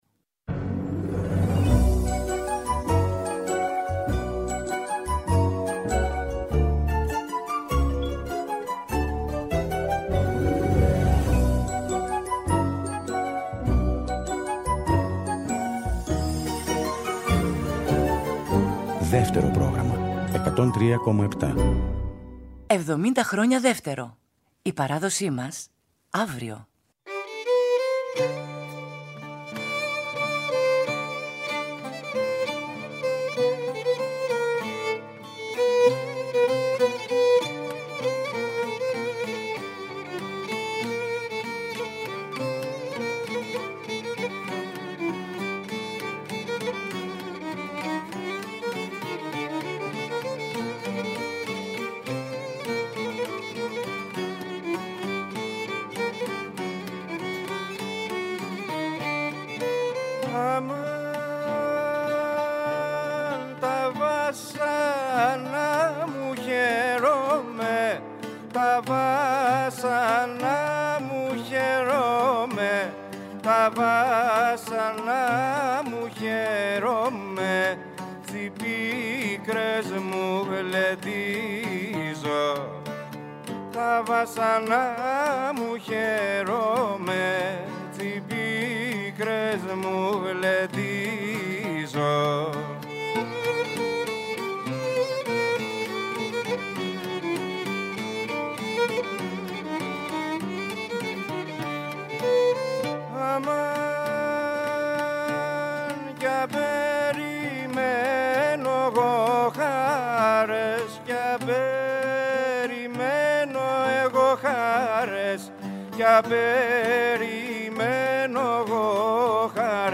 Ένας κύκλος 15 εκπομπών, που θα μεταδίδονται από τις 2 Μαΐου και κάθε μέρα έως τις 15 Μαΐου, στις 13.00, με ζωντανές ηχογραφήσεις σε Αθήνα και Θεσσαλονίκη με συγκροτήματα και μουσικές ομάδες από όλη την Ελλάδα, αλλά μουσικές συμπράξεις μόνο για τα γενέθλια του Δευτέρου Προγράμματος.
φέρνοντας μαζί τους «ατόφιους» τους ήχους της Κρήτης
Λαούτο και τραγούδι
λύρα τραγούδι
κιθάρα
Live στο Studio